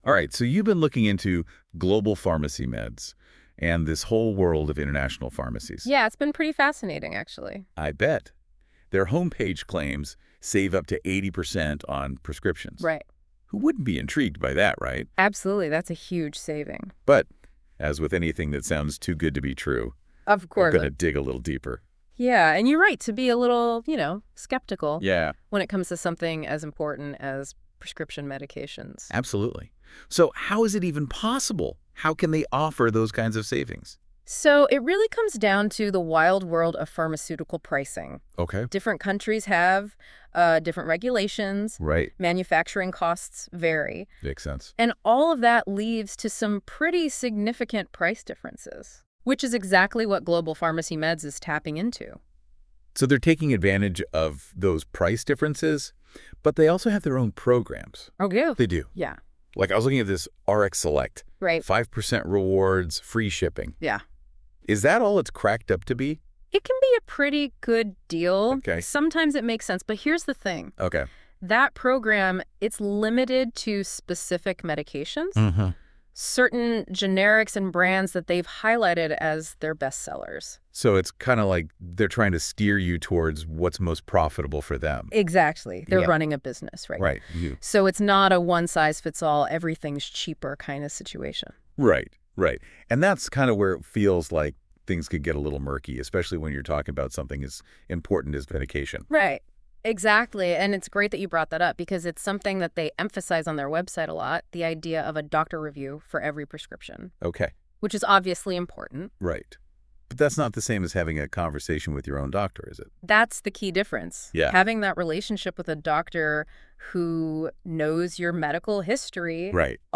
Global Pharmacy Meds – Interview